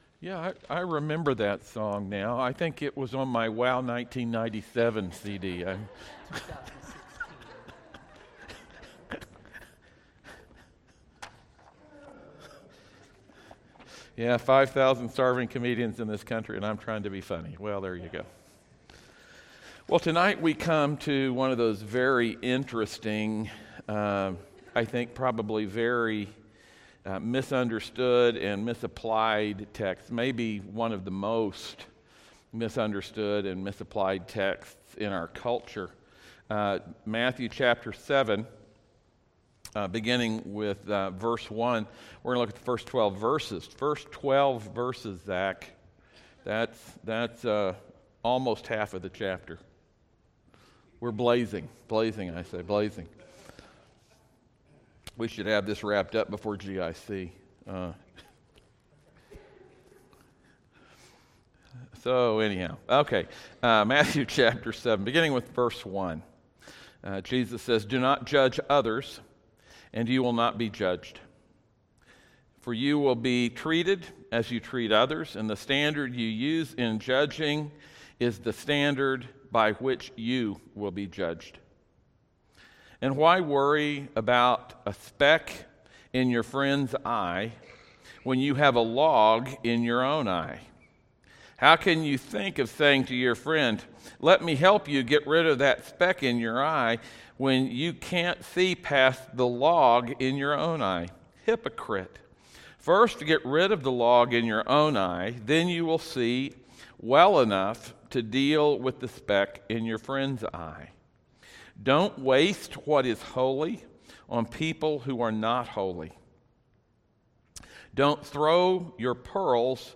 Matthew 7:1-12 Service Type: audio sermons « Bad Ideas